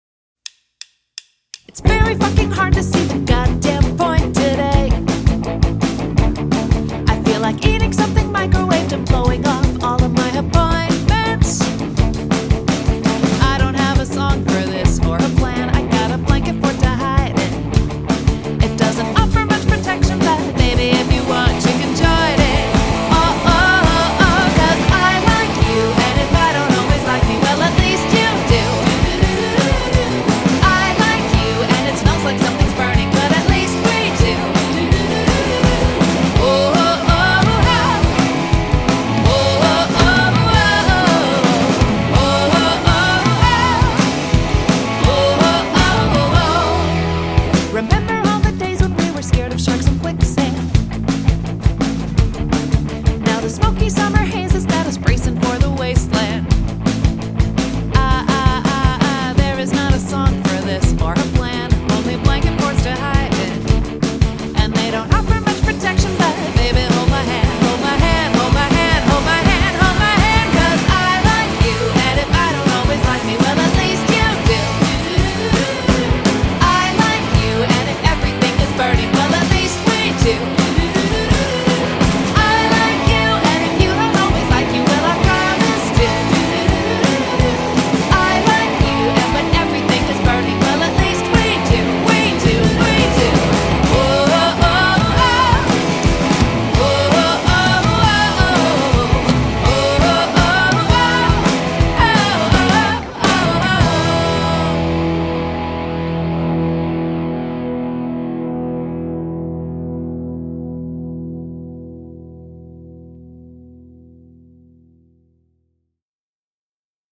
et légèrement acidulés.